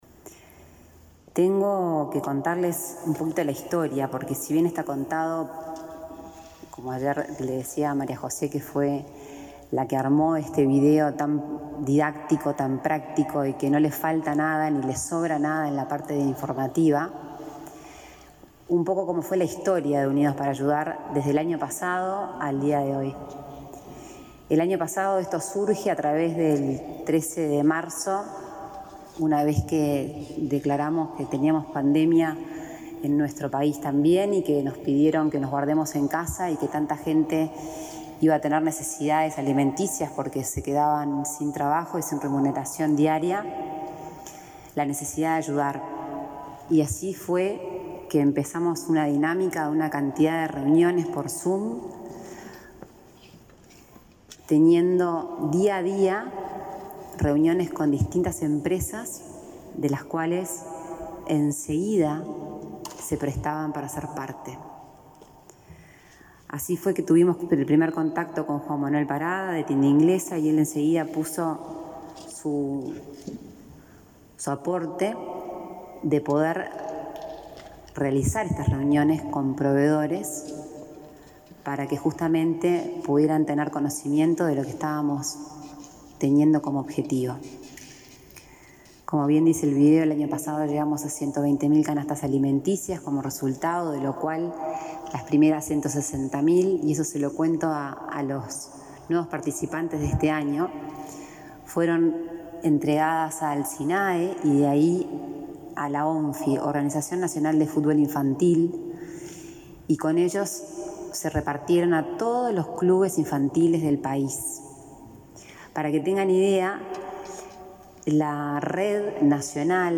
Declaraciones de Lorena Ponce de León
En el lanzamiento de Unidos para Ayudar 2021, Lorena Ponce de León, expresó que para el presente año se pretende aumentar el número de canastas